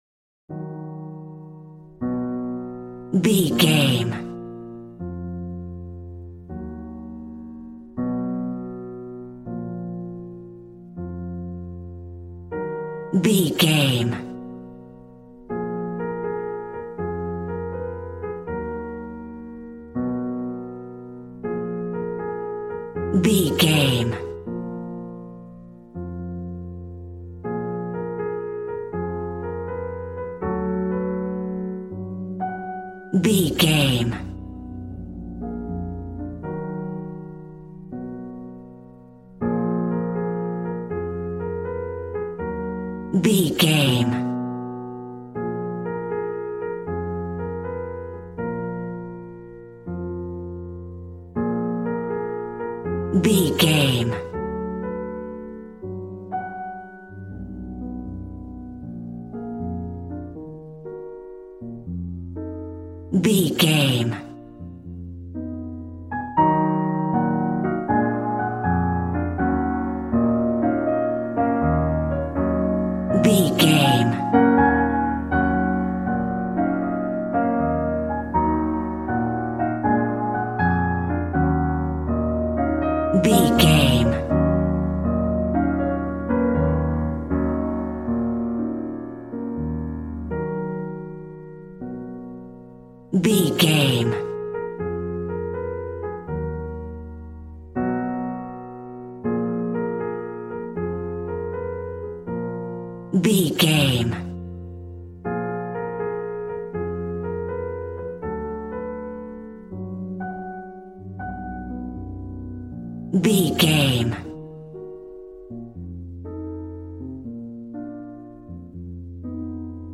Smooth jazz piano mixed with jazz bass and cool jazz drums.,
Aeolian/Minor
B♭
piano